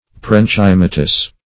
Search Result for " parenchymatous" : The Collaborative International Dictionary of English v.0.48: Parenchymatous \Par`en*chym"a*tous\, Parenchymous \Pa*ren"chy*mous\, a. [Cf. F. parenchymateux.]
parenchymatous.mp3